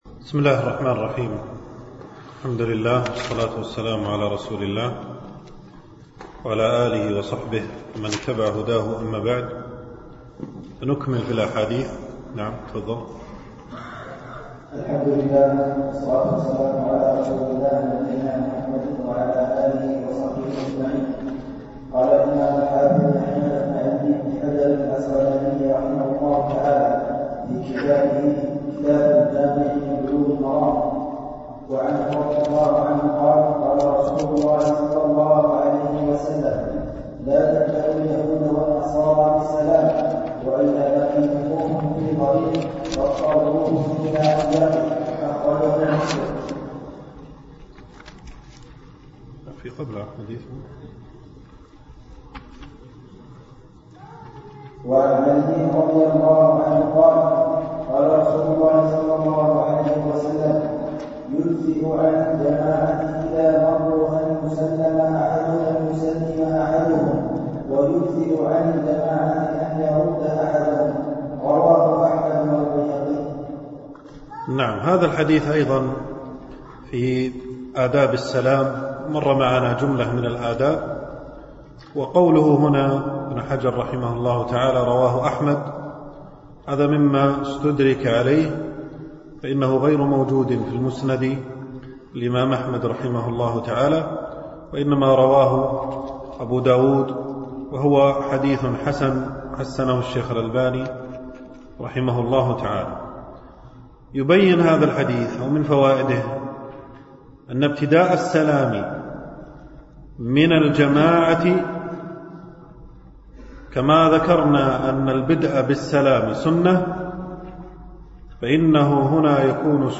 دروس مسجد عائشة (برعاية مركز رياض الصالحين ـ بدبي)